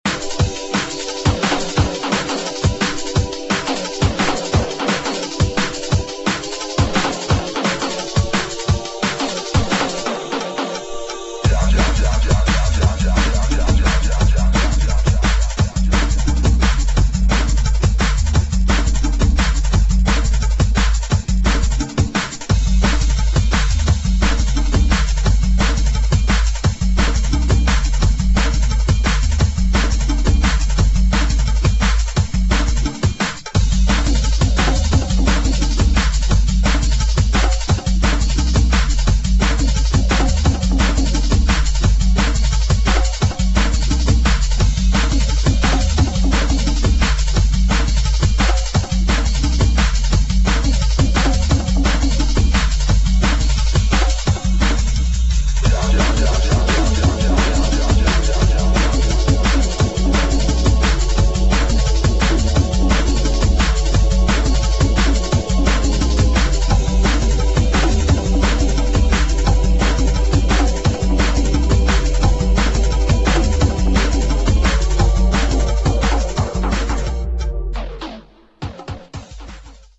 DRUM'N'BASS / JUNGLE